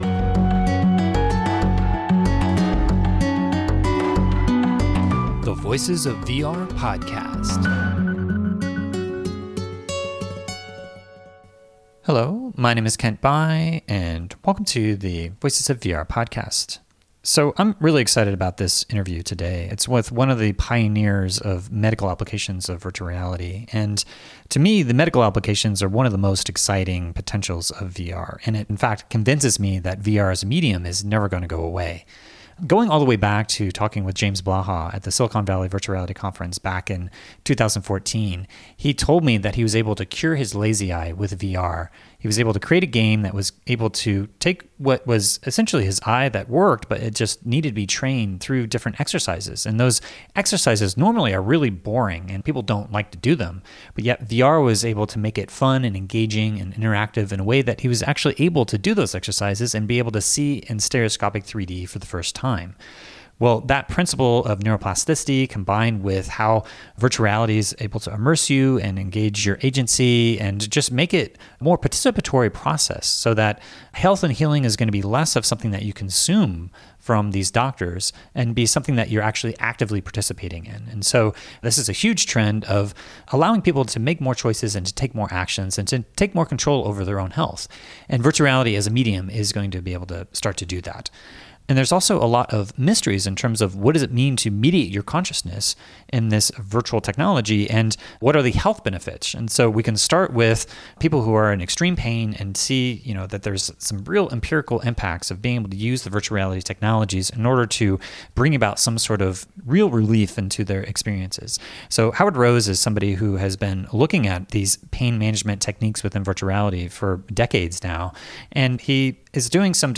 So I'm really excited about this interview today.